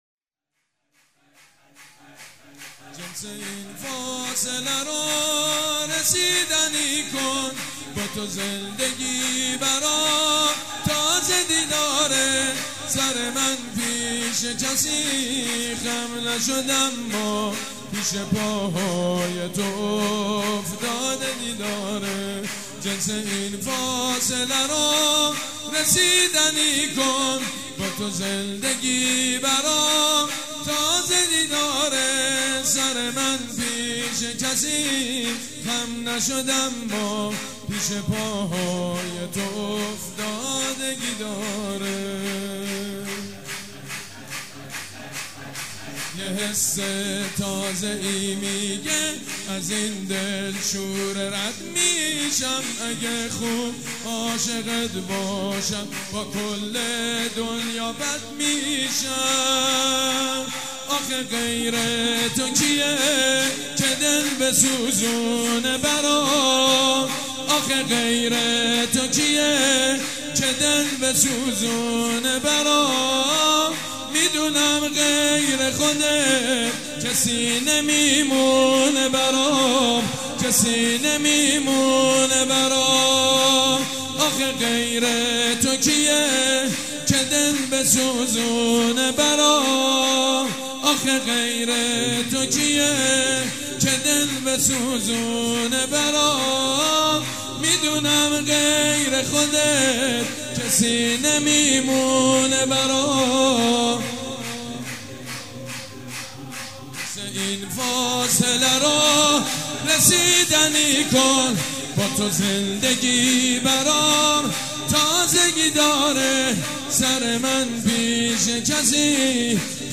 شور: جنس این فاصله رو رسیدنی کن